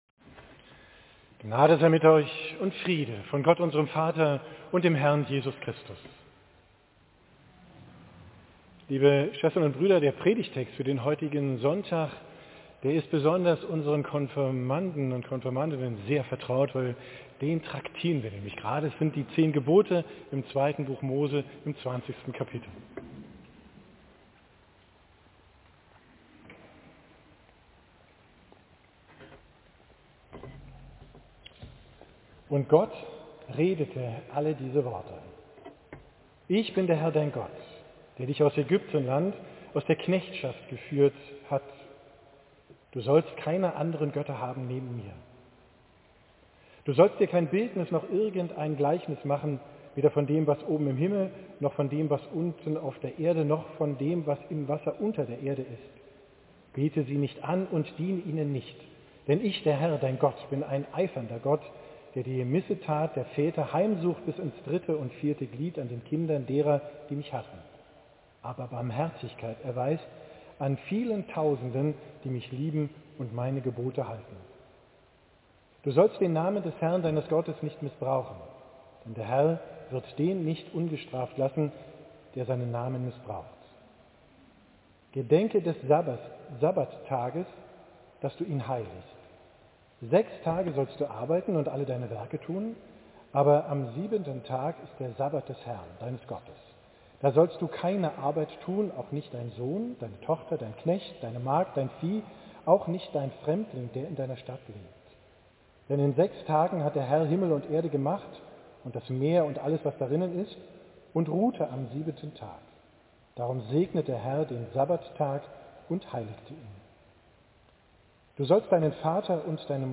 Predigt vom 18.